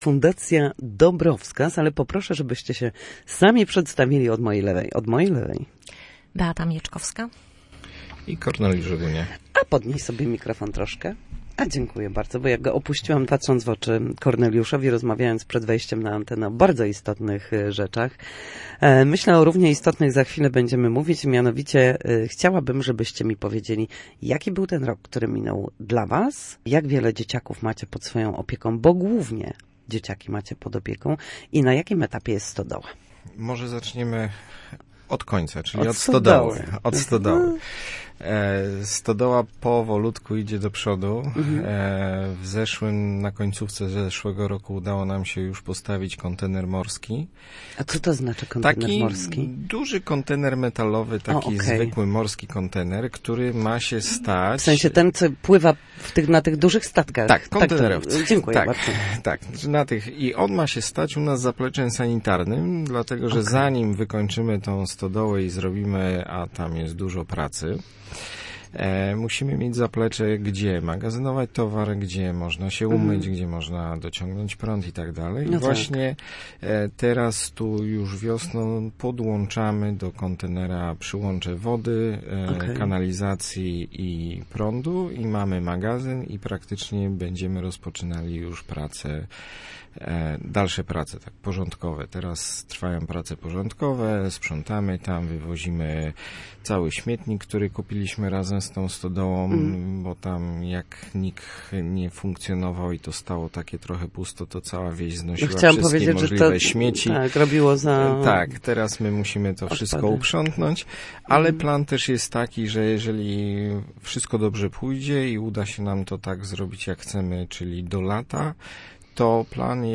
Fundacja Dobrowskaz na antenie Radia Gdańsk. Rozmawiamy o wyjątkowej świetlicy